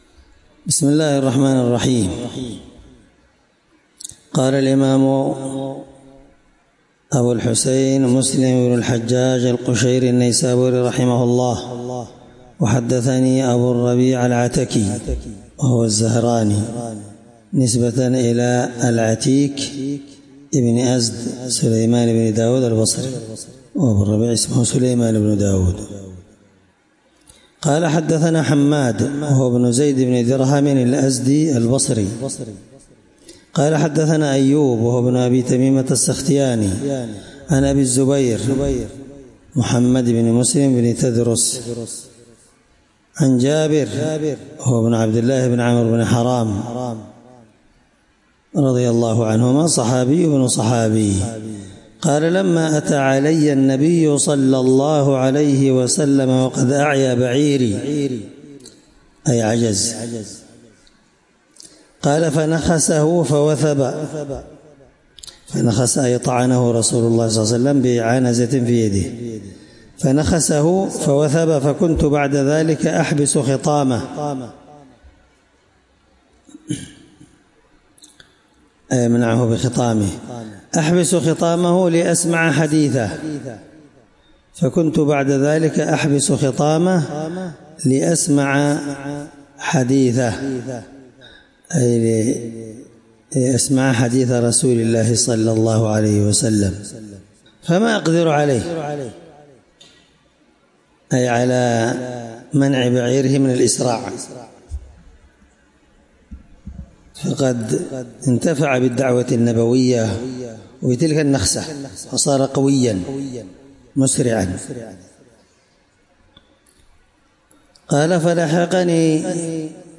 الدرس26من شرح كتاب المساقاة حديث رقم(000) من صحيح مسلم